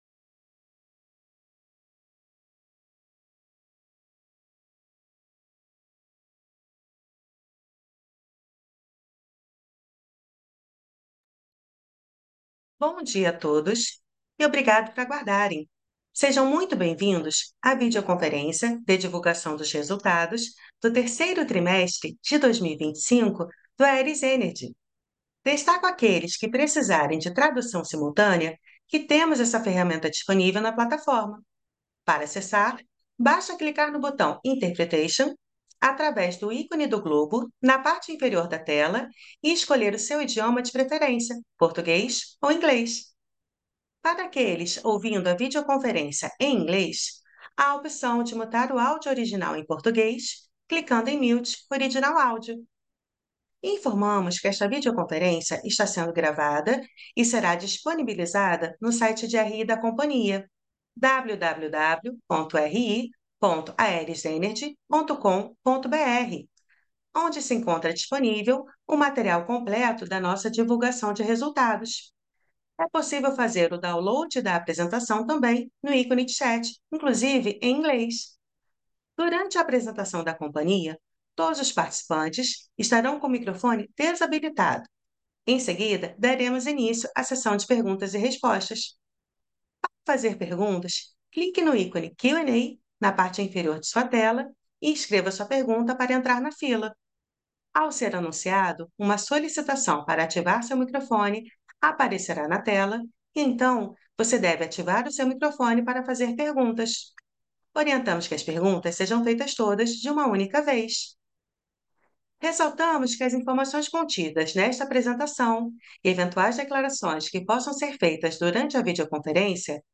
teleconferencia_3t25.mp3